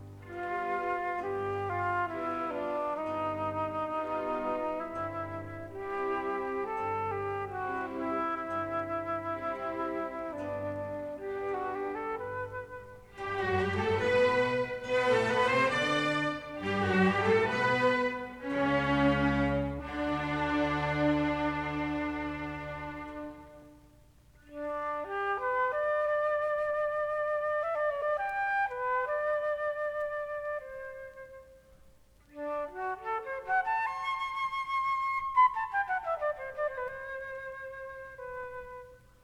This is the Adagio in a performance by the